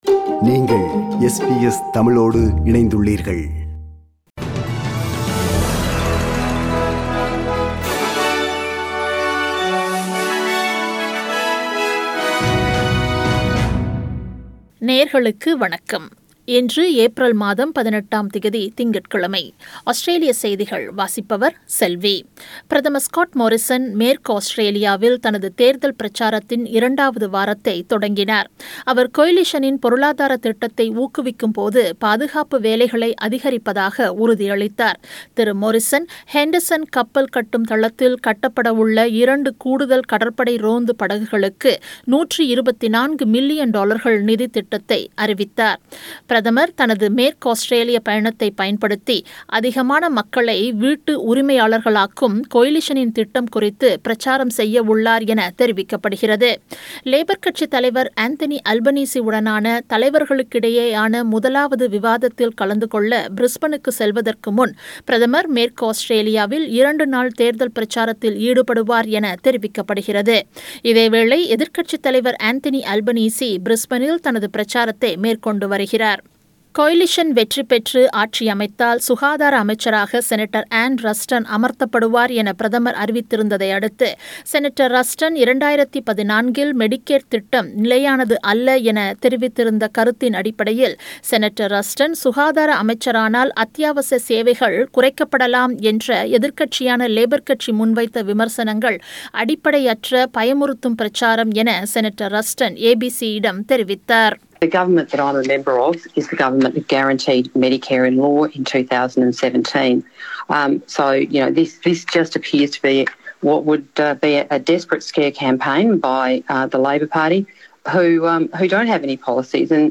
Australian news bulletin for Monday 18 Apr 2022.